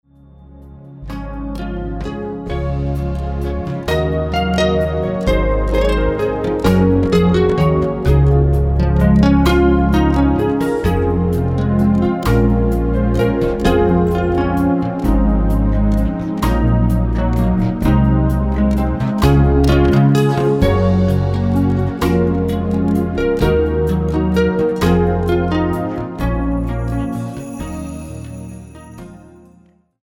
Schlagzeug
Perkussion